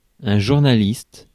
Ääntäminen
Synonyymit journaleux folliculaire gazetier Ääntäminen France: IPA: /ʒuʁ.na.list/ Haettu sana löytyi näillä lähdekielillä: ranska Käännös Substantiivit 1. žurnalist 2. ajakirjanik Suku: m .